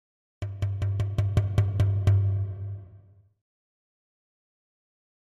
Drums Short Drumming 2 - Fast Hits - Medium Drum